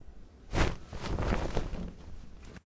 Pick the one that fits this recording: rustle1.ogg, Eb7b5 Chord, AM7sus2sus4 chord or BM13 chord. rustle1.ogg